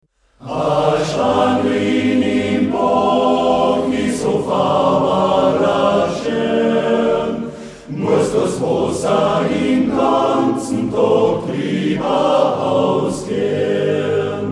Aufgenommen im Oktober 2009 in der Volksschule